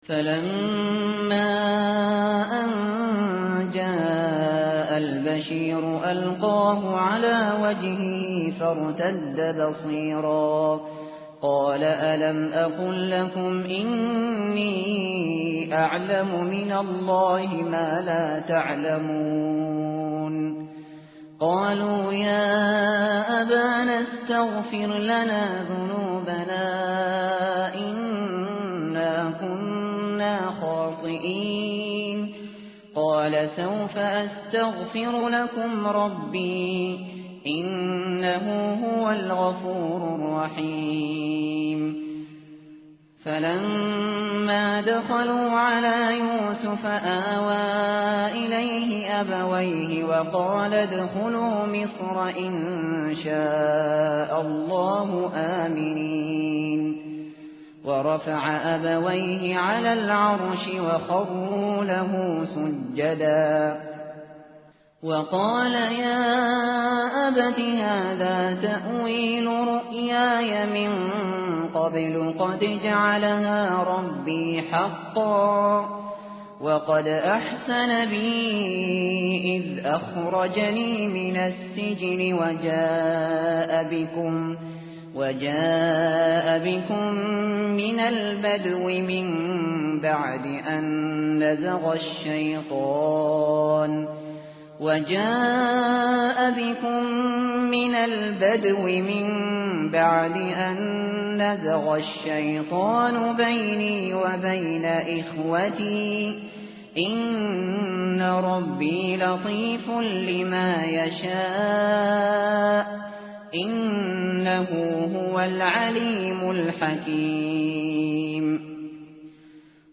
متن قرآن همراه باتلاوت قرآن و ترجمه
tartil_shateri_page_247.mp3